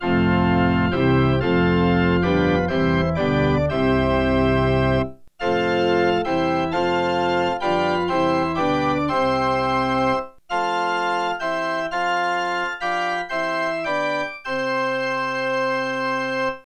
1. The source .wav file is “organ anechoic sample.wav”.
Figure 8: FFT of Anechoic SourceFigure 8: FFT of Anechoic Source
organ-anechoic-sample.wav